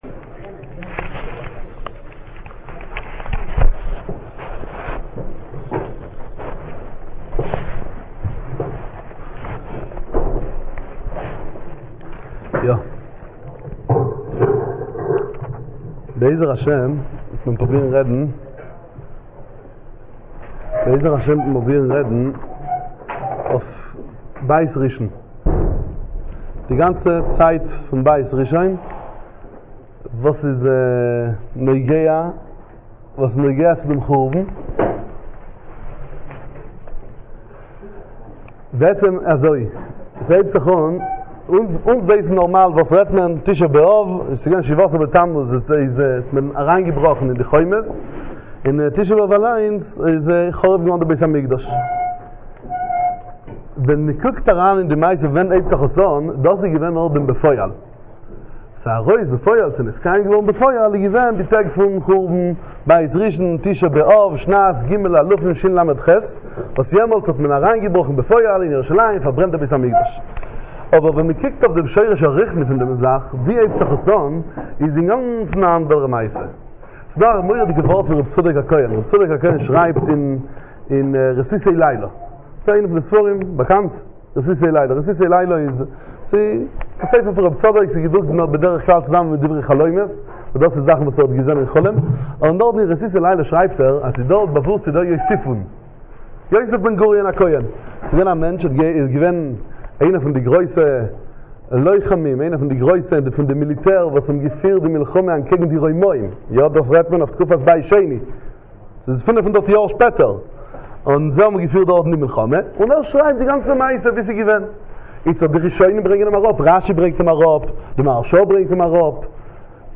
סיפור חורבן בית ראשון - ישיבה דחסידי גור מארצות הברית